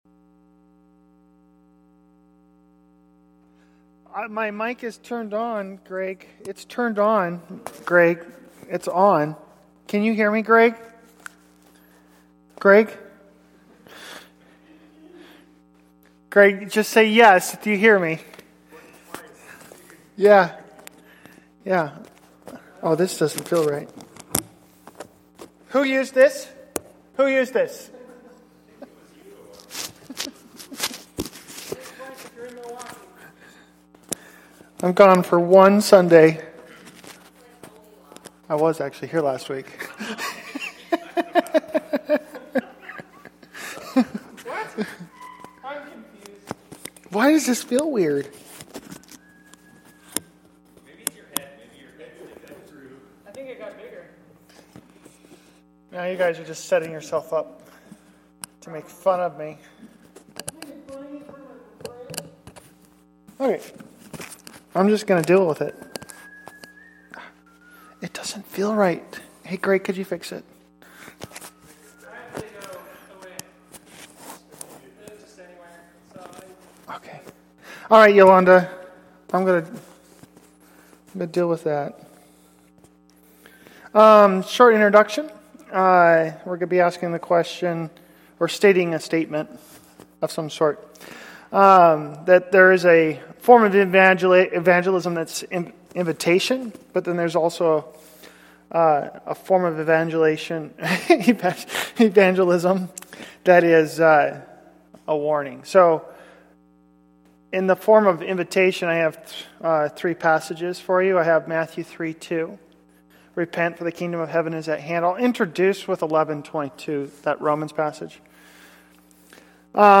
Sermons by Reliance